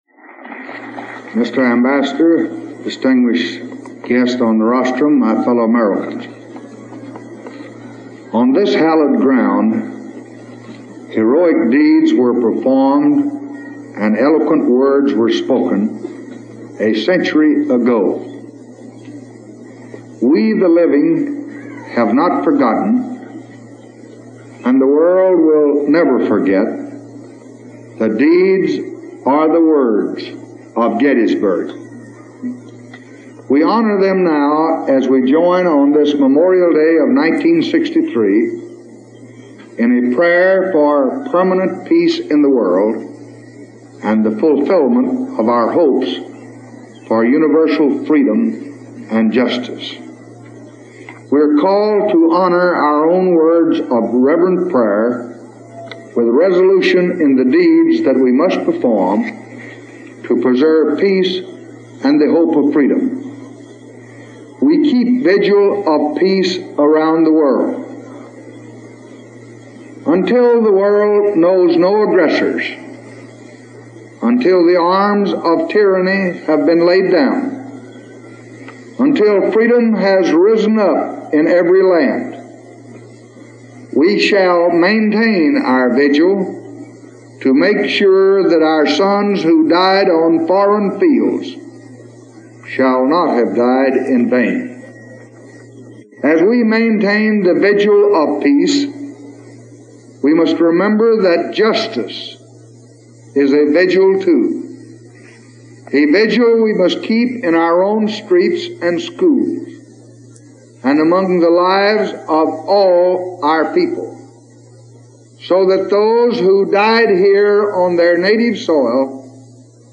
Memorial Day Address at Gettysburg
delivered 30 May 1963, Gettysburg, Pennsylvania
Audio Note: Digitally filtered